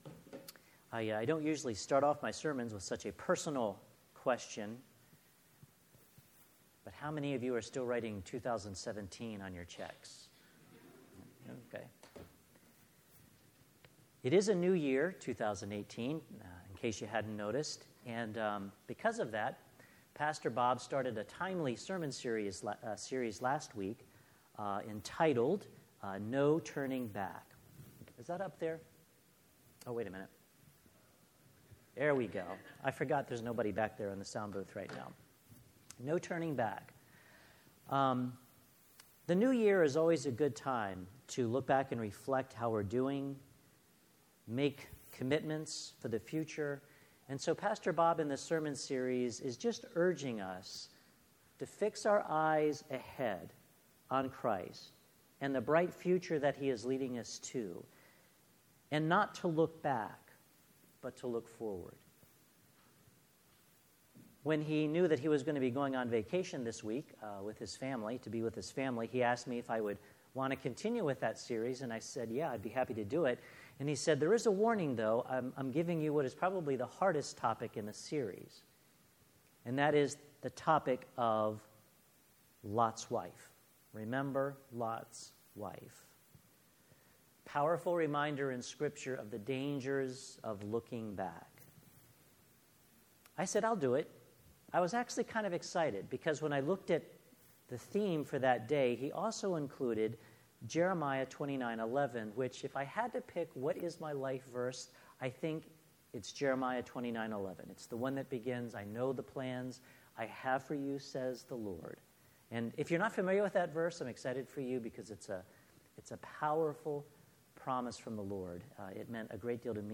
Sermon: […]